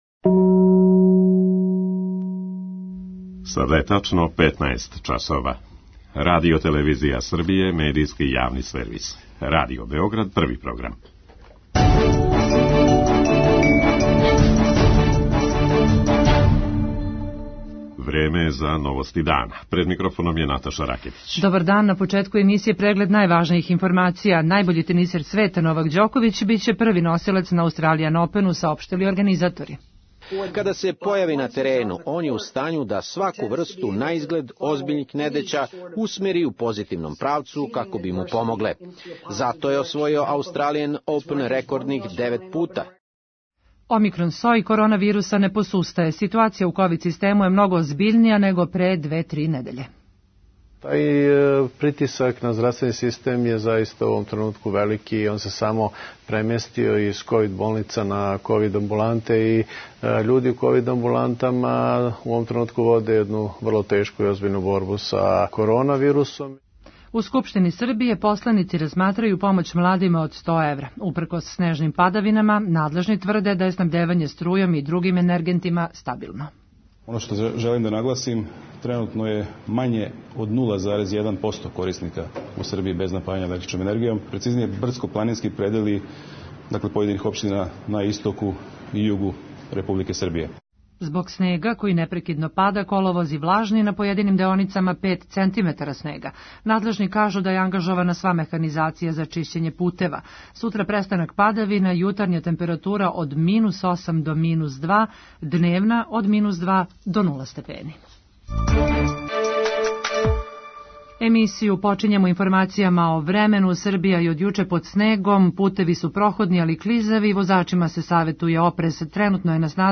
централна информативна емисија Првог програма Радио Београда